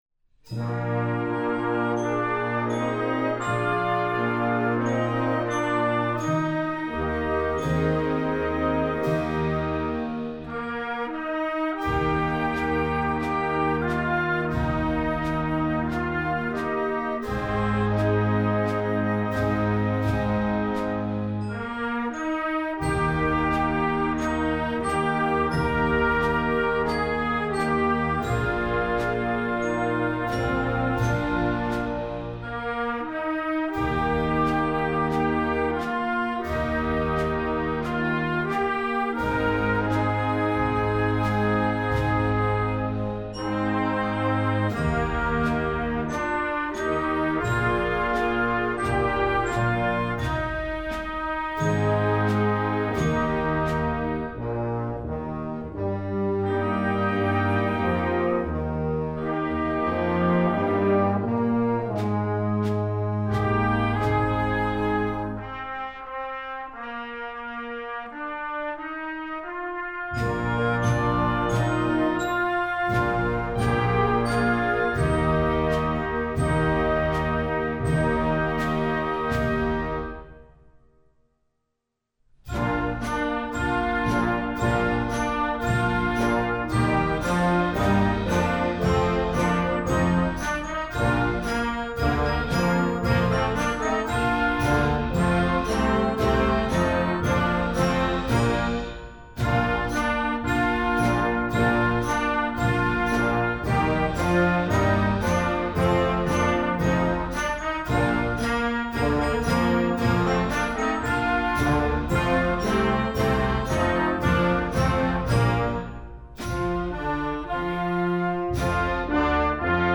Instrumentation: concert band
instructional, children